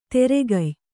♪ teregay